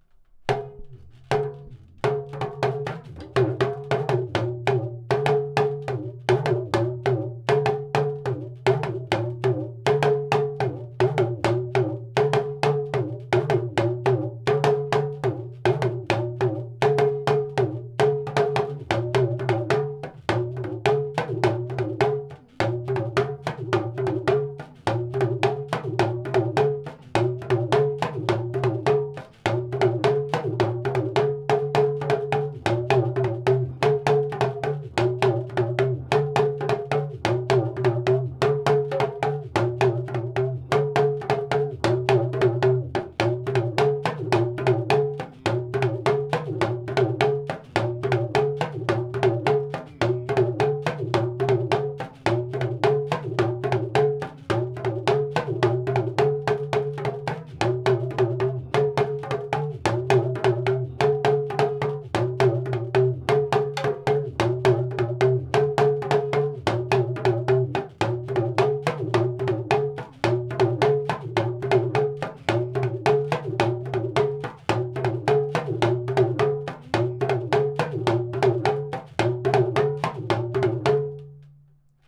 Nakohi-waa lead lunga drumming audio
African drumming
Dagomba drumming Talking drums